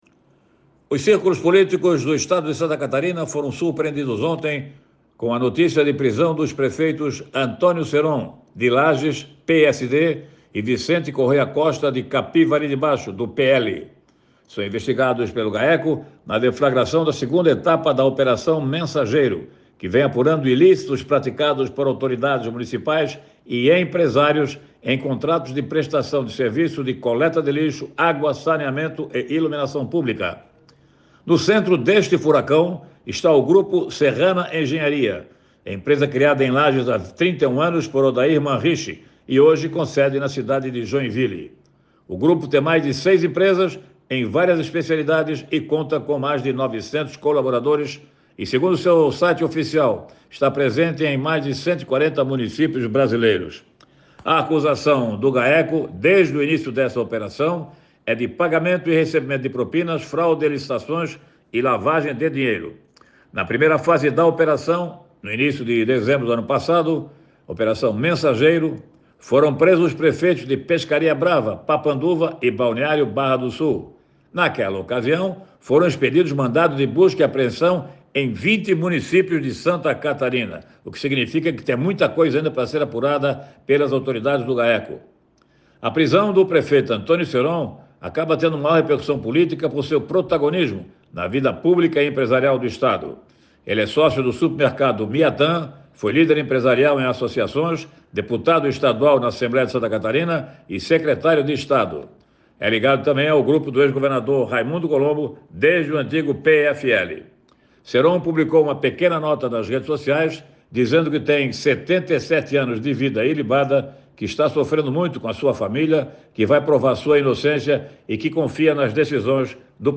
O jornalista aponta que com a notícia de prisão de dois prefeitos surpreenderam os círculos políticos em Santa Catarina